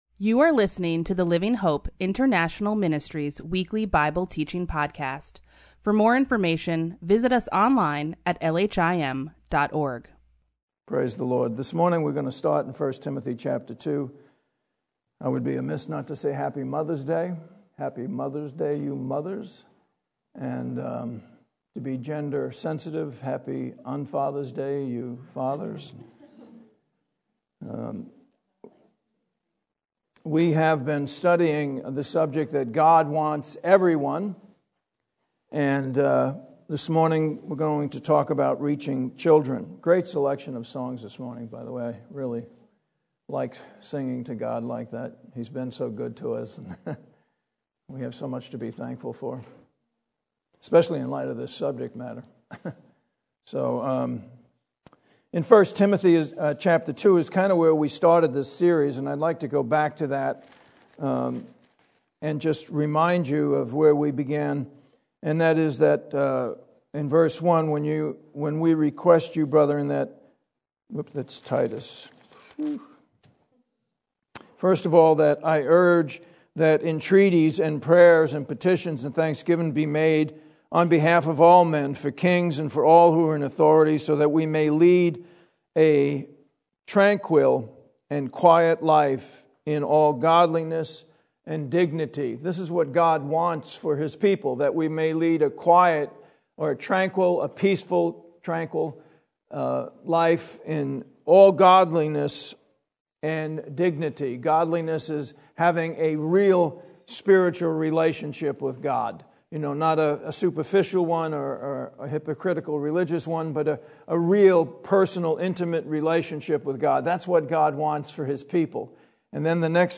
LHIM Weekly Bible Teaching